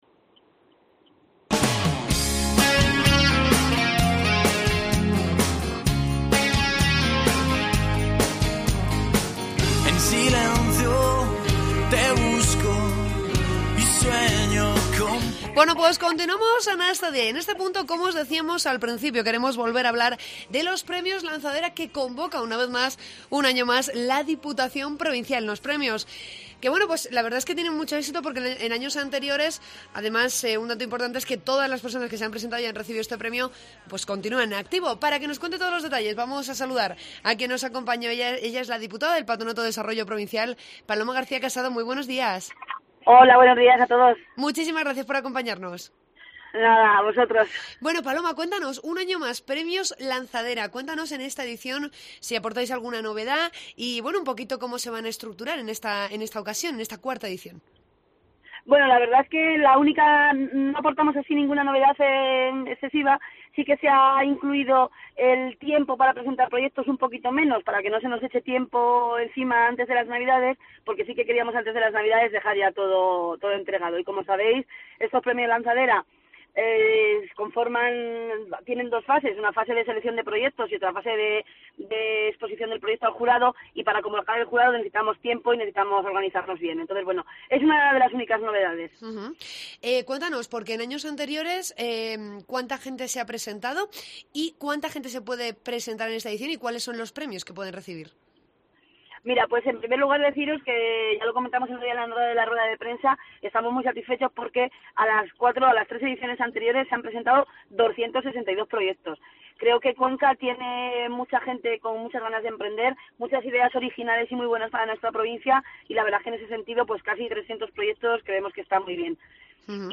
Escucha la entrevista con la diputada del Patronato de Desarrollo Provincial, Paloma García Casado.